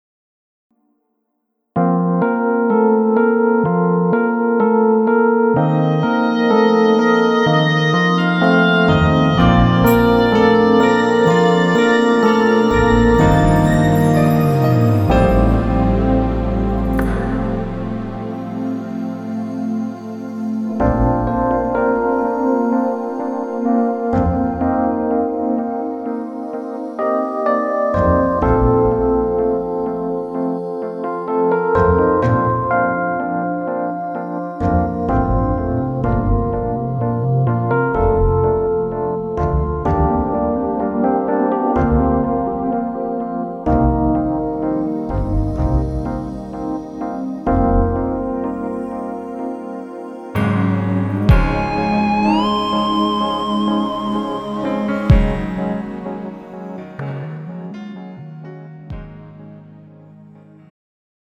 음정 -2키
장르 축가 구분 Pro MR
가사   (1절 앞소절 -중간삭제- 2절 후렴연결 편집)